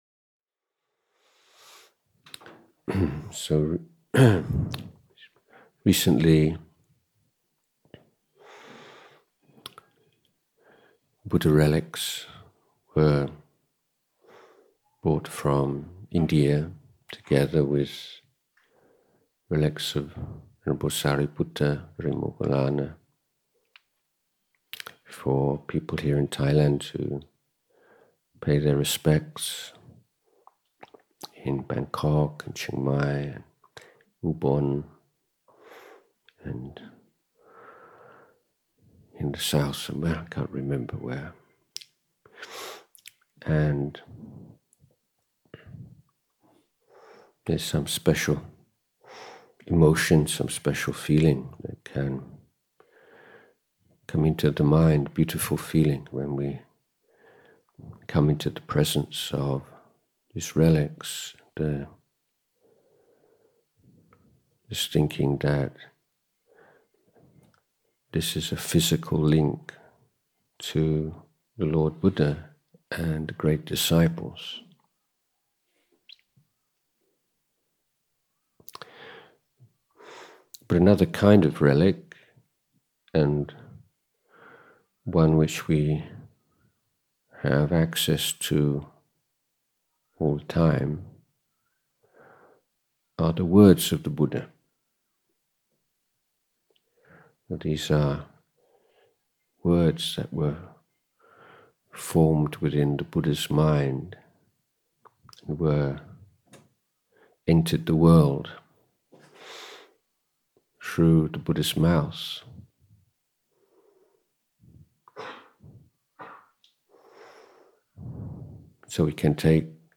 English Retreat at Bahn Boon, Pak Chong, Nakhon Rachasima, 20-24 March 2024